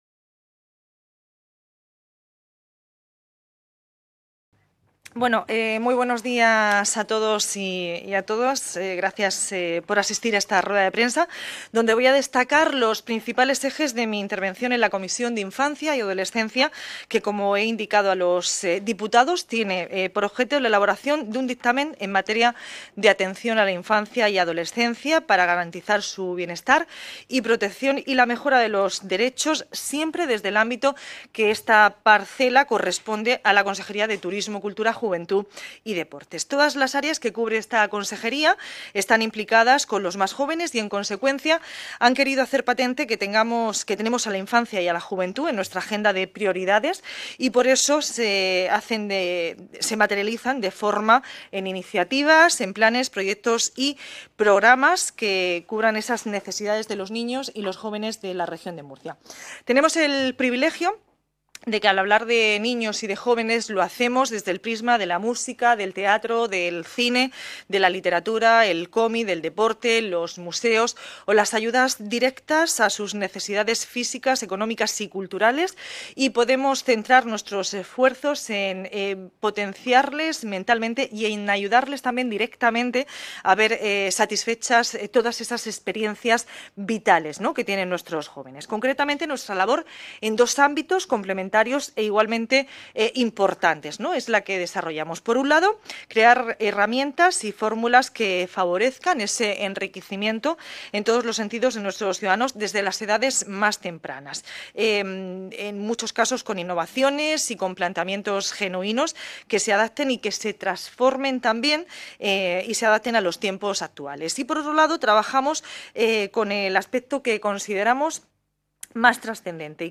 Ruedas de prensa posteriores a la Comisión Especial de Estudio sobre Infancia y Adolescencia | Asamblea Regional de Murcia